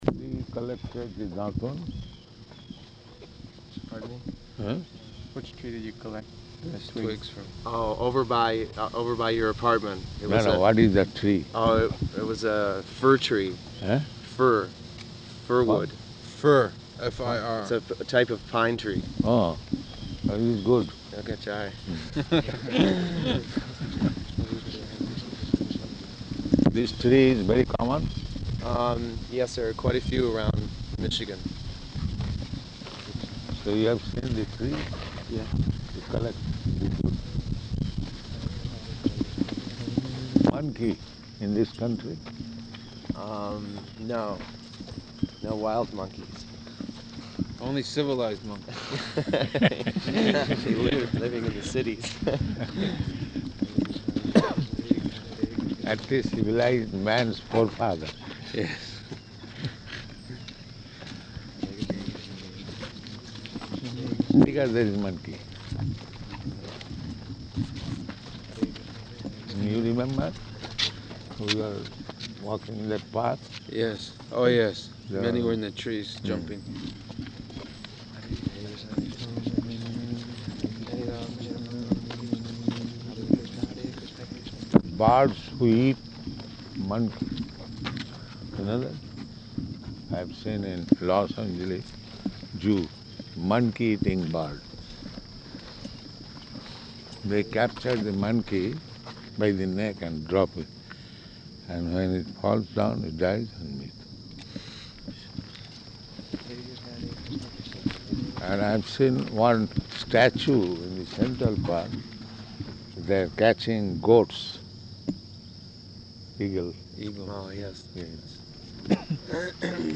Type: Walk
Location: Detroit